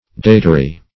Datary \Da"ta*ry\, n. [LL. datarius.